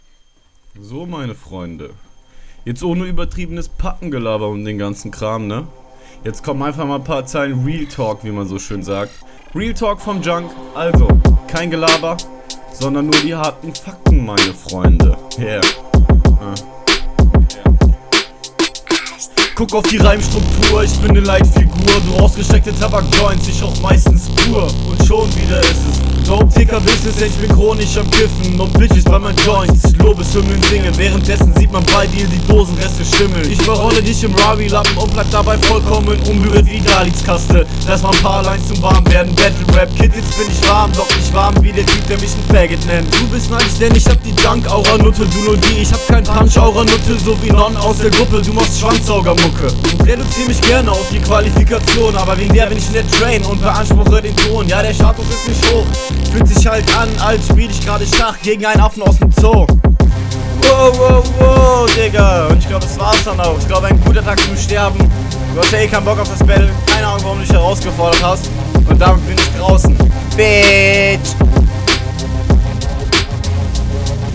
cool getextet, strukturiert formulierte zeilen, lässig geflowt. brutal. schöne konter. klar, da sind noch einige …
Flow: Ich finde deinen Flow und deine Betonungen routinierter als bei deinem Gegner.